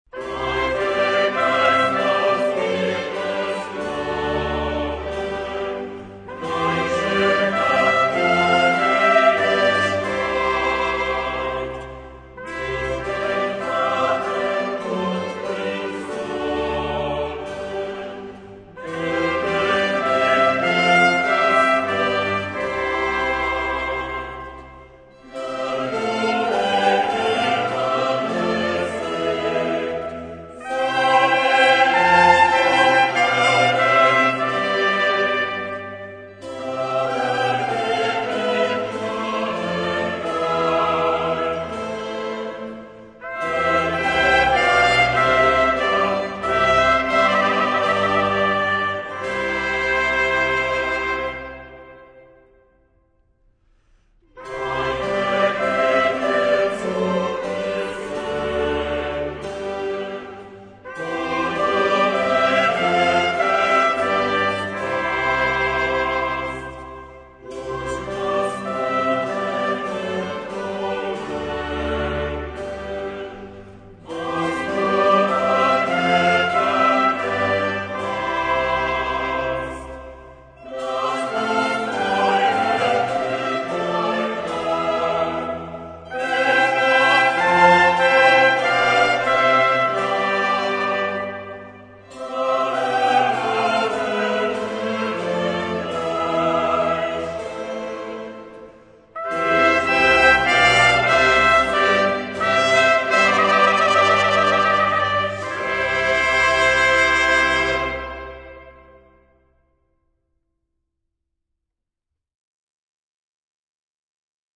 live-Aufnahme
Choral -
Heilger Geist ins Himmels Throne (C-Dur, 4/4 (tutti, ohne Blfl))